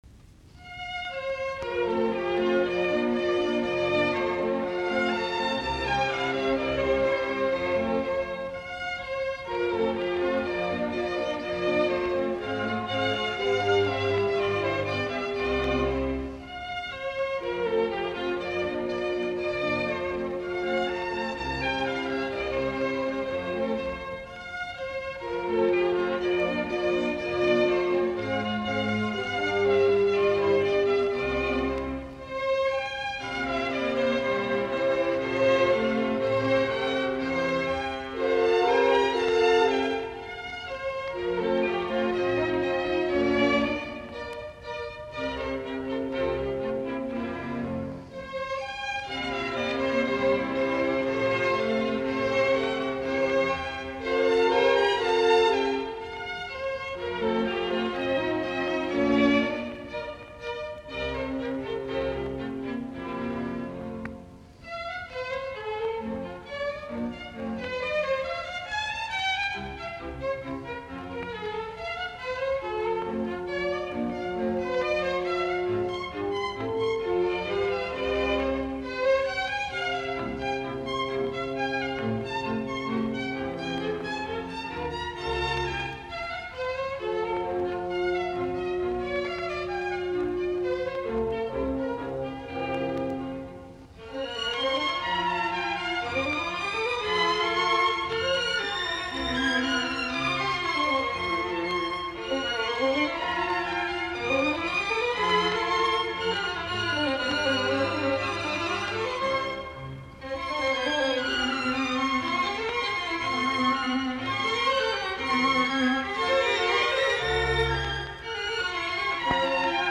musiikkiäänite